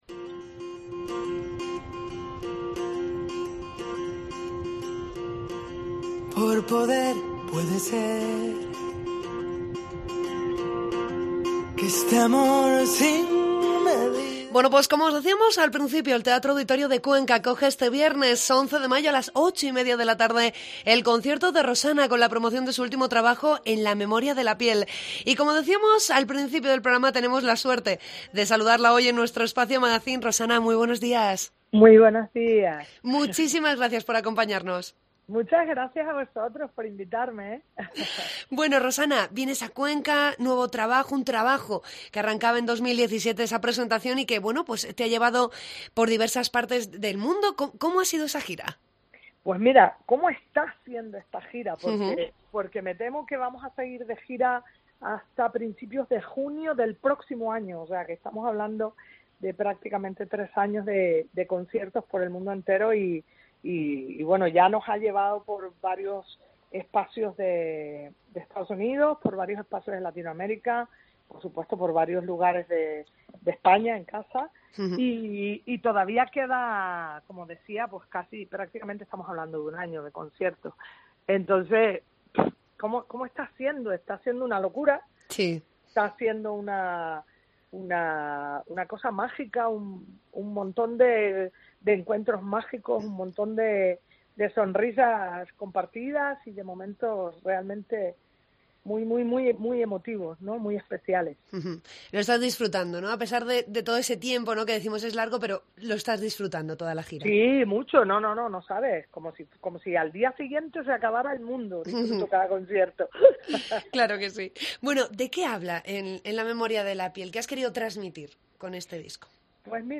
AUDIO: Entrevista con la cantante Rosana.